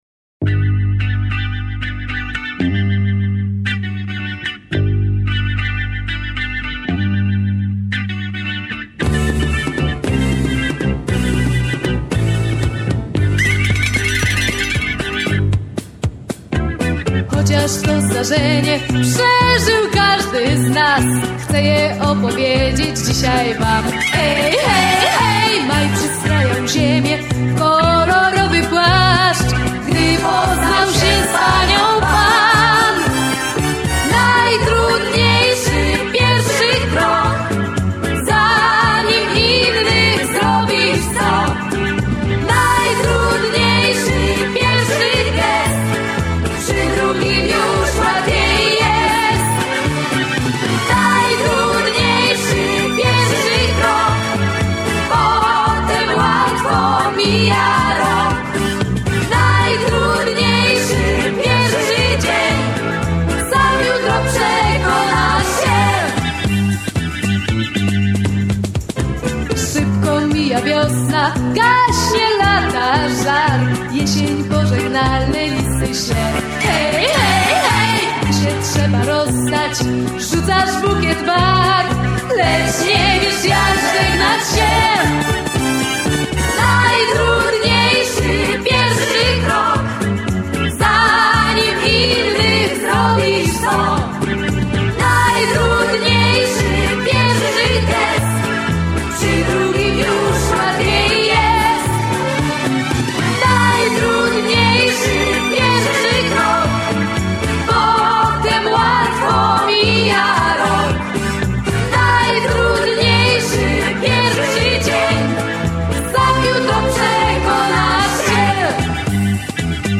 Opole '79